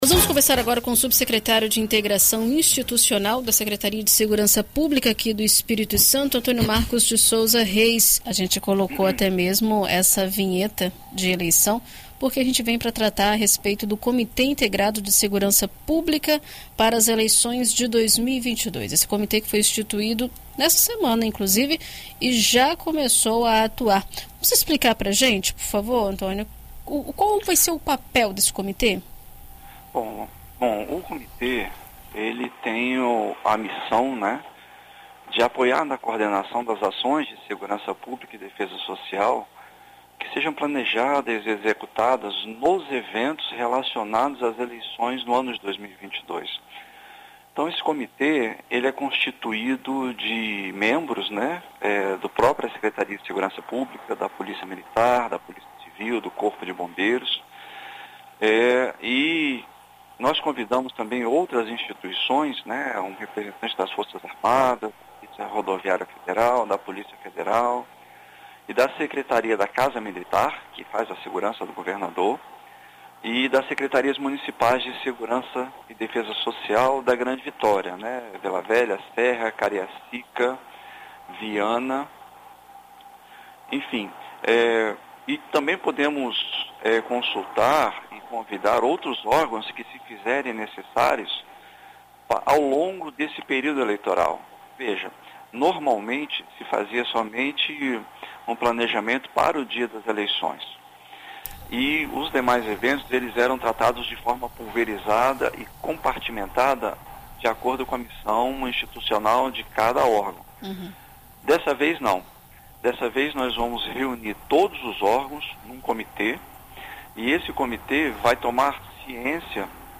Em entrevista à BandNews FM Espírito Santo nesta quinta-feira (15), o subsecretário de Integração Institucional da Sesp, coronel Antônio Marcos de Souza Reis, explica como irá funcionar o grupo e quais serão os objetivos do comitê durante o período eleitoral.